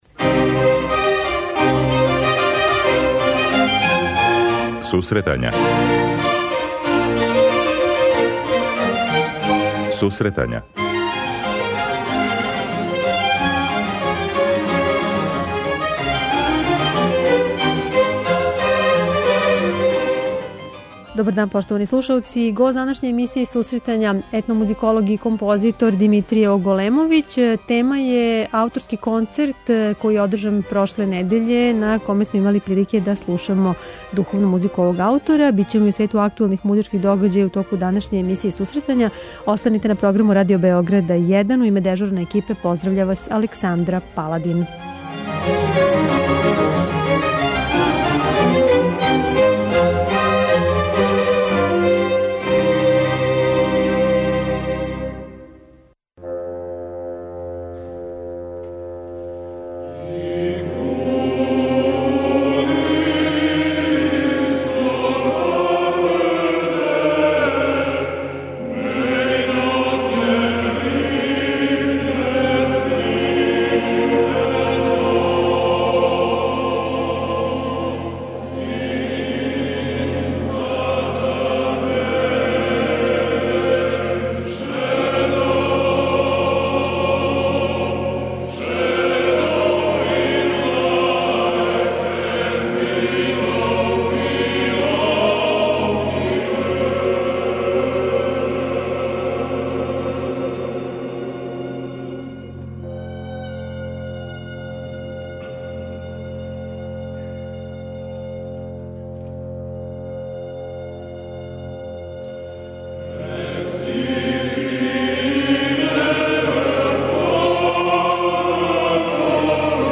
преузми : 26.17 MB Сусретања Autor: Музичка редакција Емисија за оне који воле уметничку музику.